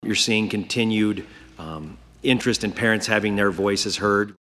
Speaker Pat Grassley, the top Republican in the House, says there are several issues related to parental involvement in education.